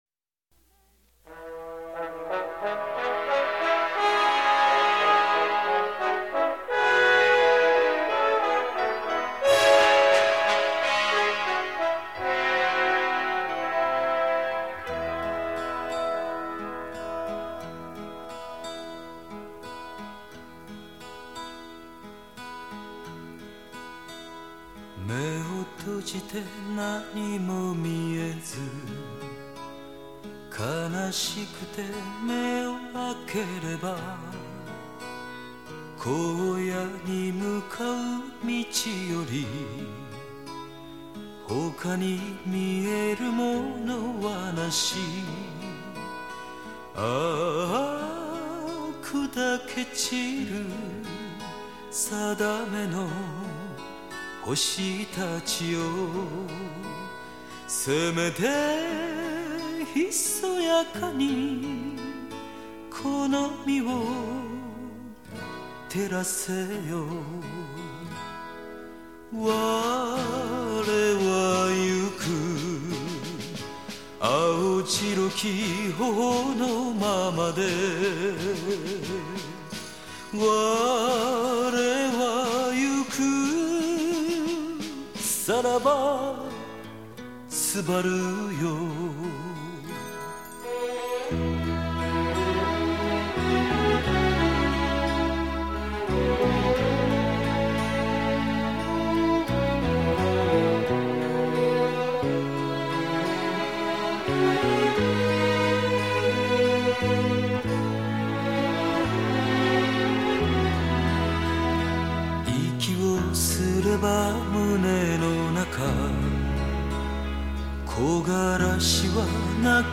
收录日本演歌精选/曲曲动听 朗朗上口